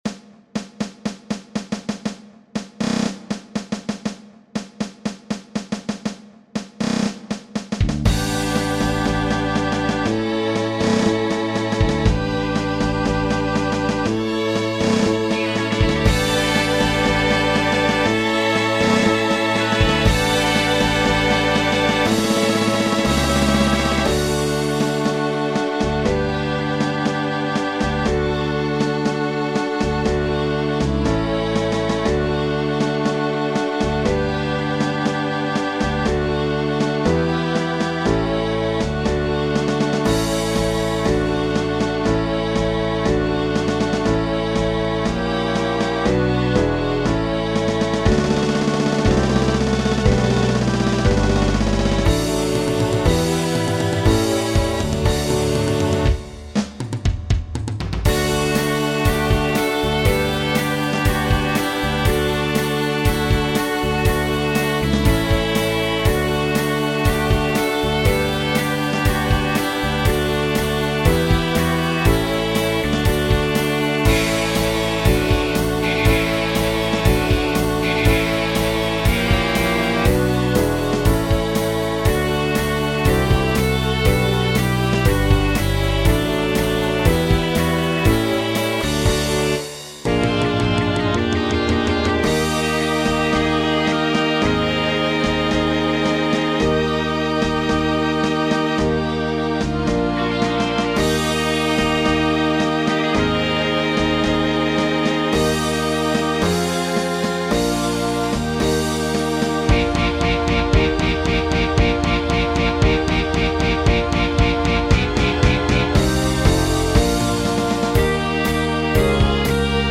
Runterladen (Mit rechter Maustaste anklicken, Menübefehl auswählen)   Ode an die Freude (Playback)
Ode_an_die_Freude__5_Playback.mp3